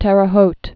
(tĕrə hōt, hŭt)